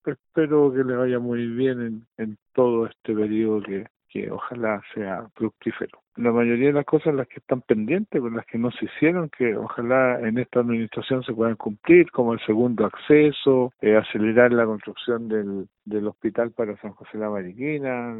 Tal es el caso del alcalde de Mariquina, Rolando Mitre, quien manifestó su deseo de que el nuevo Gobierno resuelva algunas demandas, como el segundo acceso y acelerar la construcción de un hospital para la comuna.